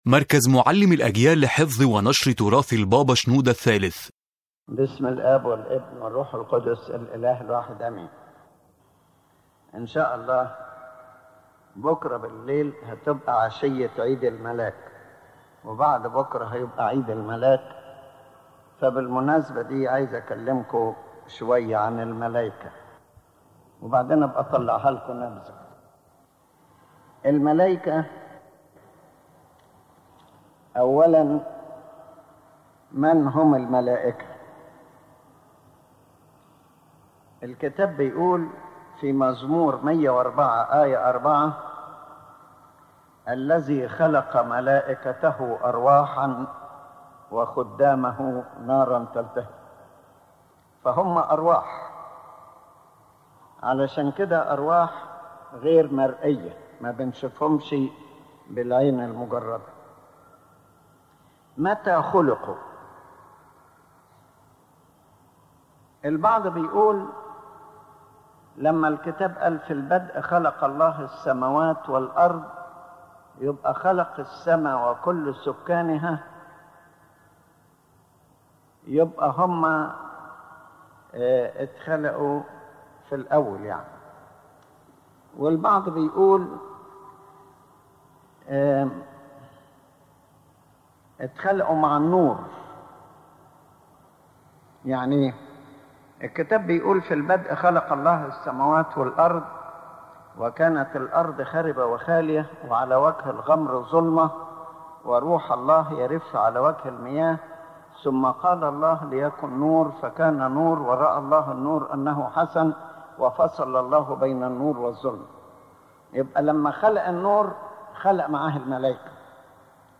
The lecture speaks about the nature of angels as presented by the Holy Bible and the church teaching, explaining who the angels are, how God created them, their ranks and works, and the spiritual virtues that characterize them, while clarifying the difference between the fallen angels (demons) and the holy angels.